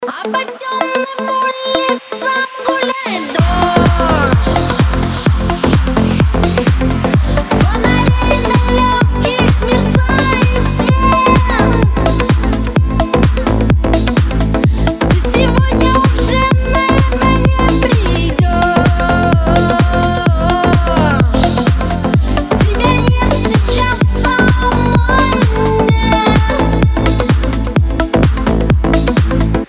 • Качество: 320, Stereo
поп
женский вокал
remix
dance